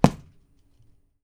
PUNCH D   -S.WAV